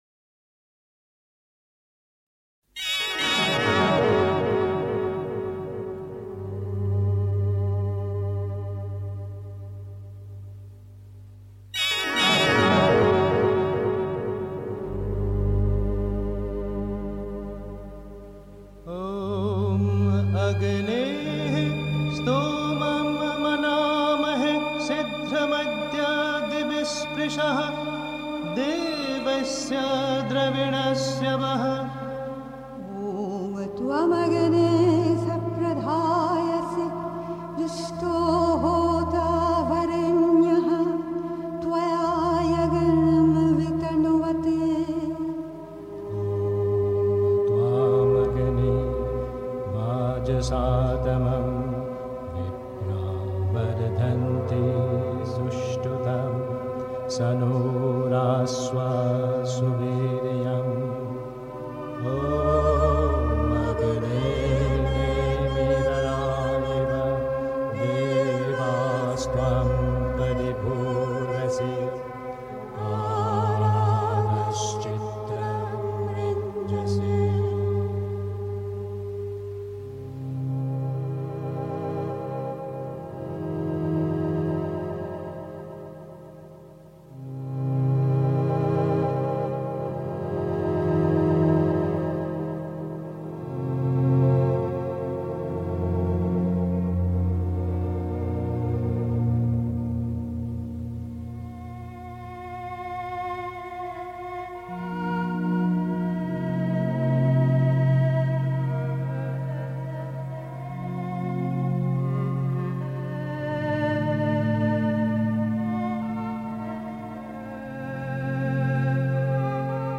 Sich dem Göttlichen mit Vertrauen und Zuversicht hingeben (Sri Aurobindo, CWSA Vol 29, p. 69) 3. Zwölf Minuten Stille.